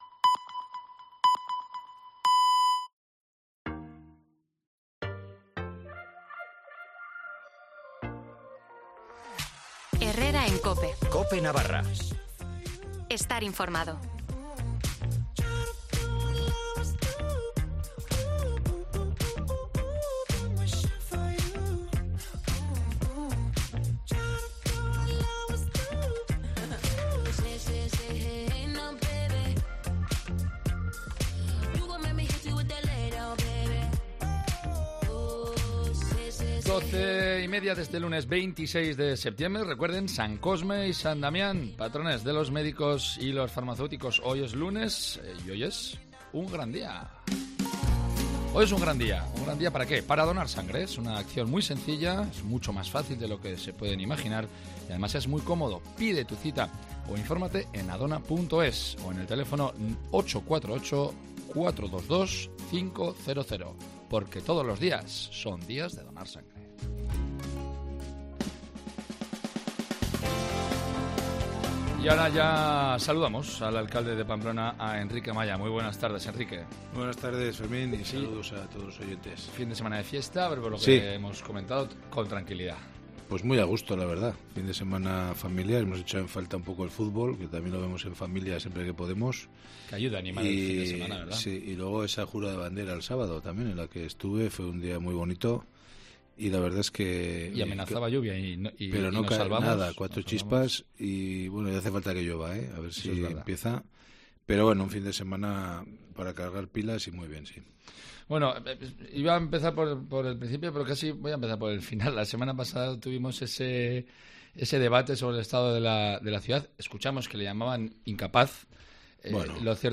AUDIO: Enrique Maya, alcalde de Pamplona, en Cope Navarra: ¿Inseguridad nocturna en las calles de Pamplona?, movilidad, aparcamientos disuasorios,...